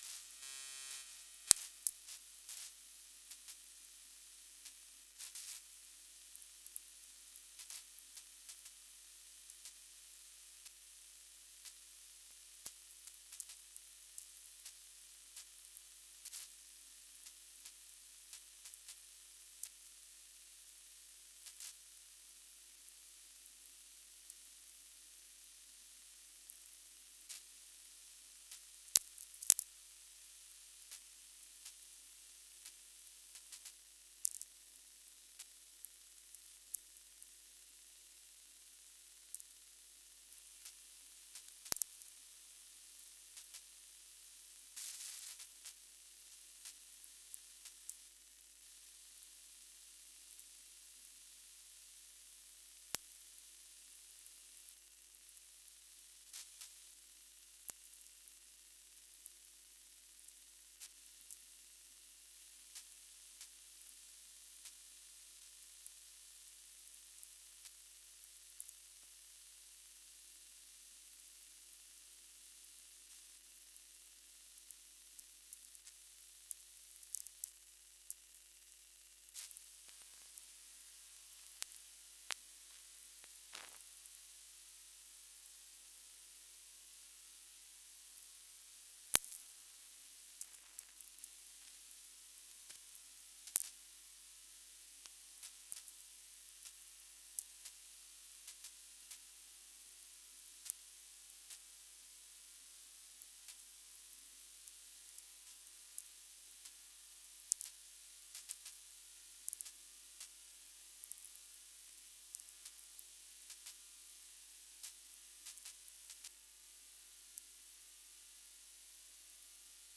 E-Feld antenna on fiberglas rod - about 5m above ground.
Recording with FocusRite Scarlett 2i2
Sadly, SAQ was not received here.